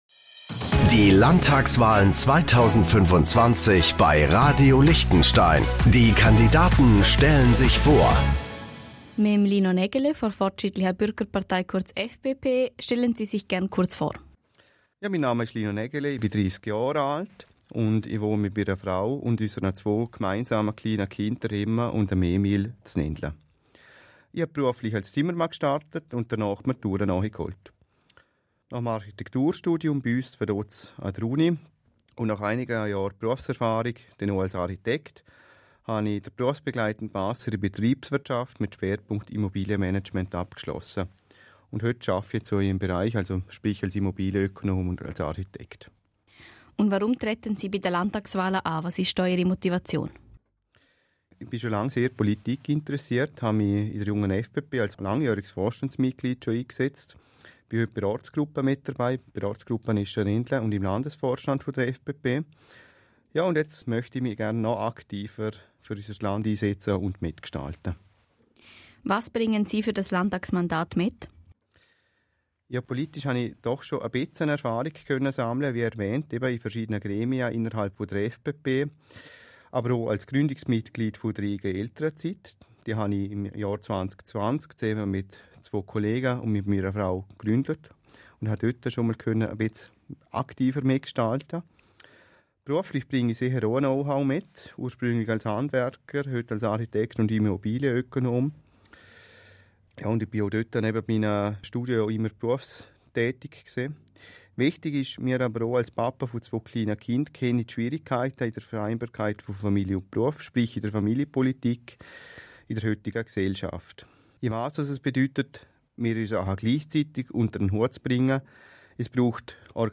Landtagskandidat